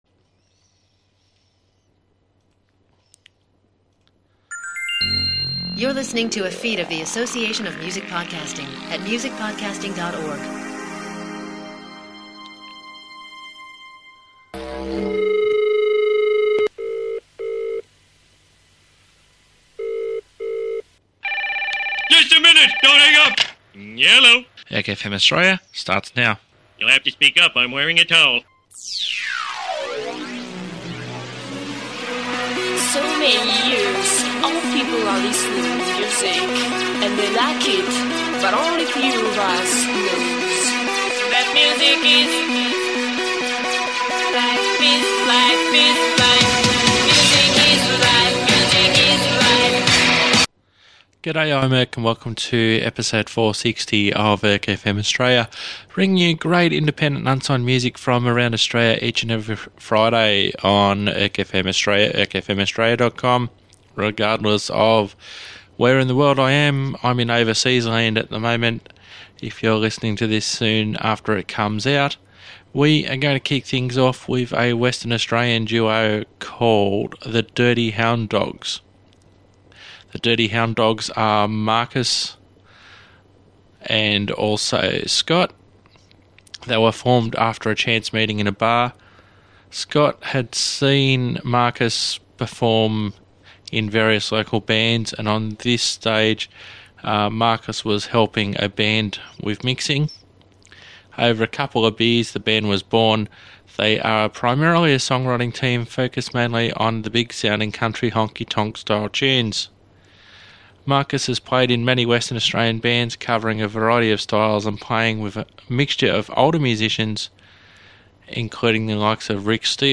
acoustic, bluegrass, Americana (Australiana?) and country
This episode is a little (ok, a lot!) bluegrassy!